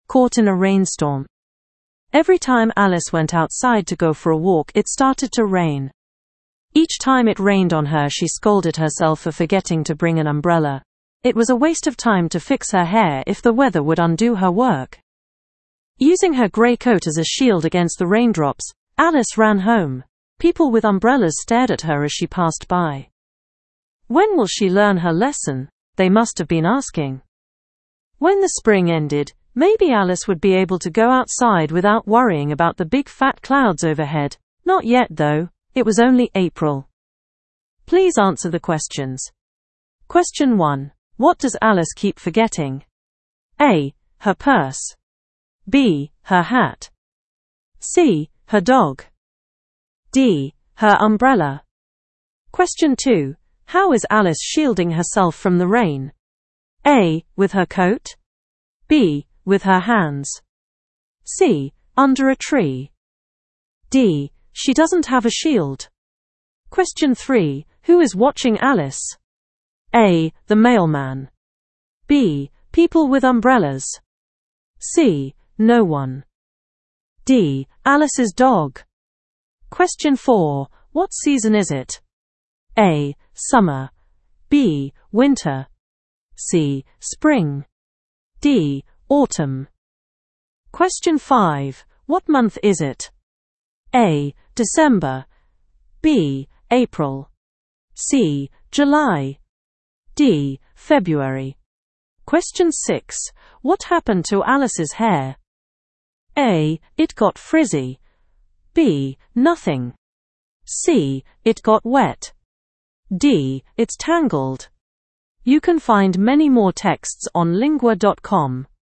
Inglaterra